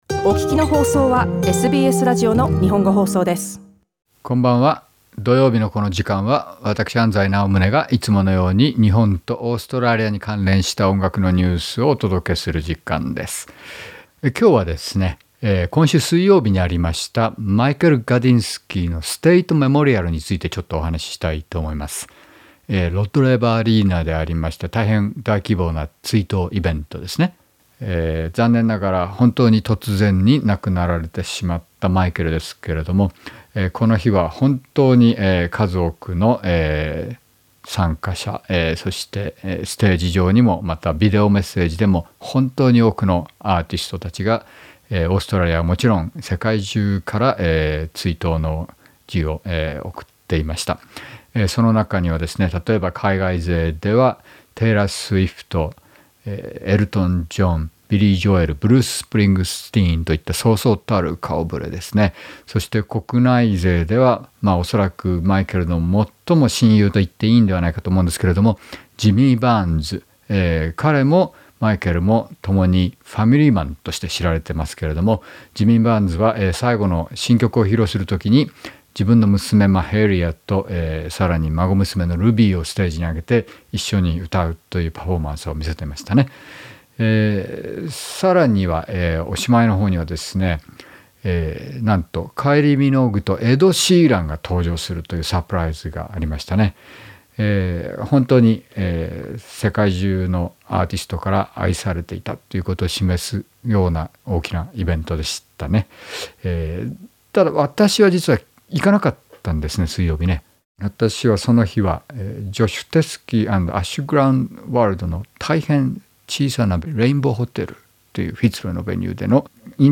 オーストラリアの最も魅力的なブルースボーカリストの共演です。